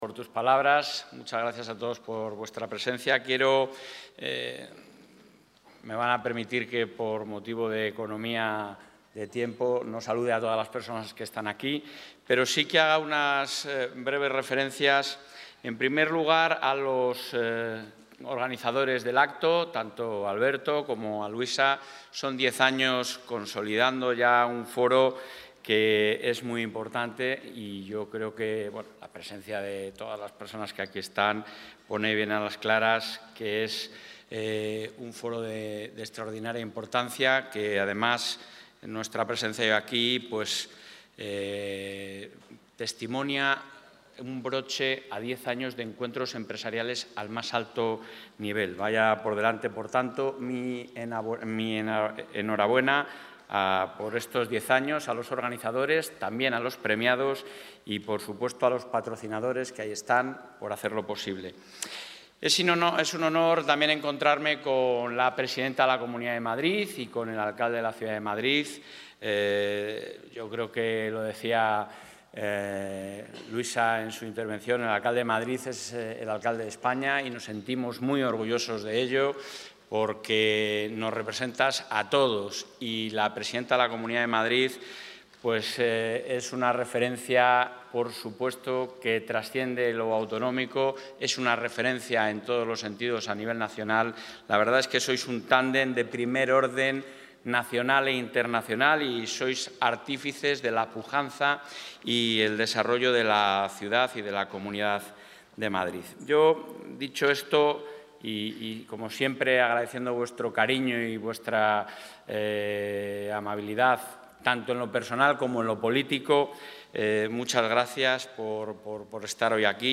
Intervención del presidente de la Junta.
En la clausura del X Foro Guadarrama, Encuentro Empresarial Castilla y León-Madrid, el presidente de la Junta ha asegurado que la recuperación de impuestos como el de Sucesiones y Donaciones sería perjudicial para la economía, las familias y la pervivencia de las empresas. Asimismo, ha recordado que la Comunidad es una tierra industrializada, competitiva e idónea para realizar nuevas inversiones, crecer e innovar.